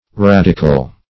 Radicle \Rad"i*cle\ (r[a^]d"[i^]*k'l), n. [L. radicula, dim. of